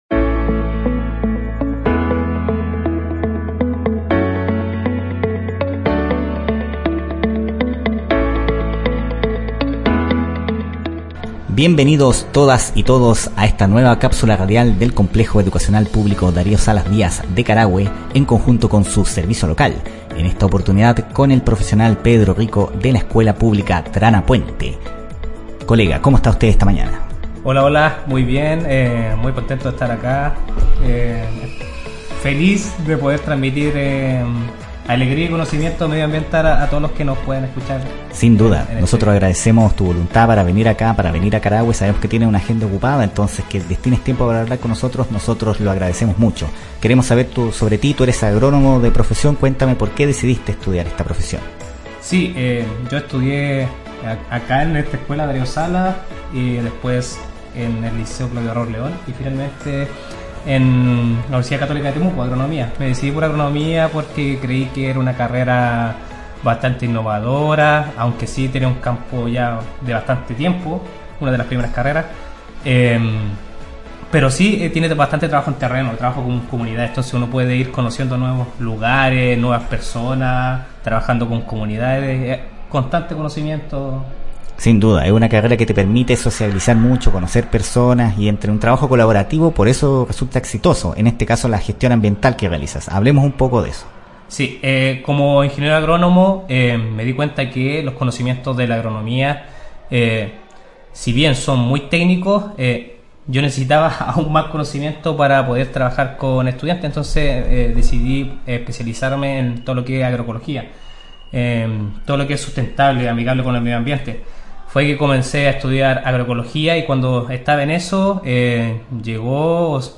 El Servicio Local de Educación Pública Costa Araucanía (SLEPCA) y el Complejo Educacional Darío Salas Díaz de Carahue, se unieron para realizar Cápsulas Radiales en el Locutorio del establecimiento educacional.